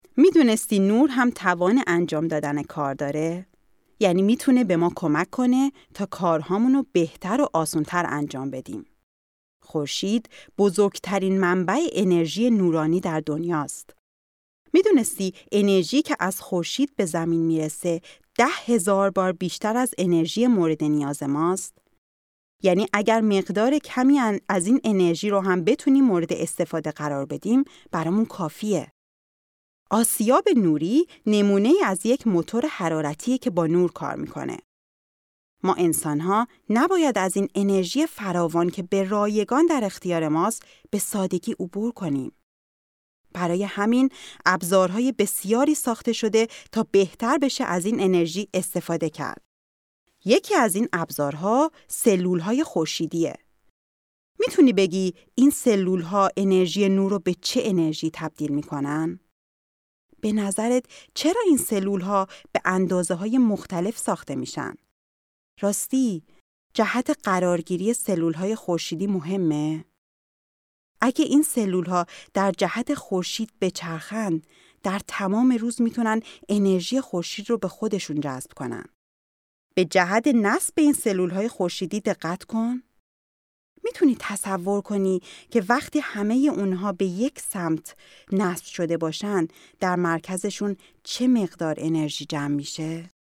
Sprechprobe: eLearning (Muttersprache):
03 Lernprogramm.mp3